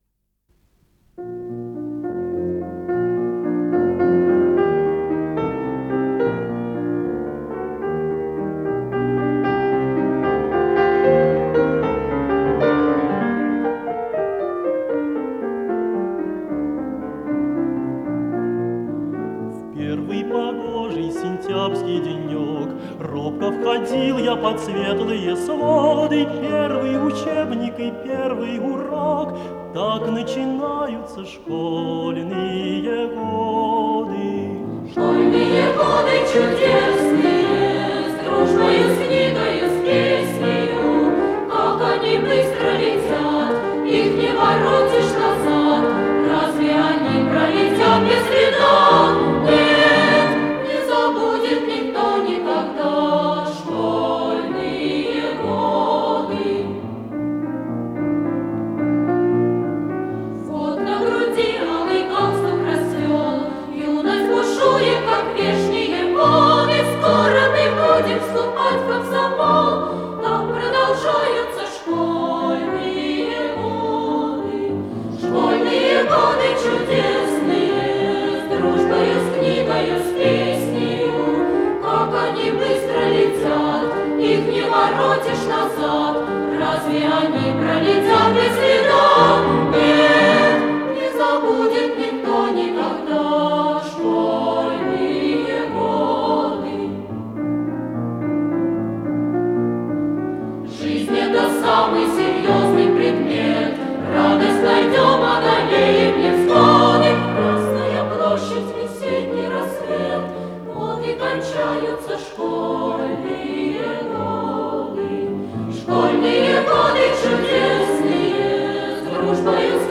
с профессиональной магнитной ленты
фортепиано
ВариантДубль моно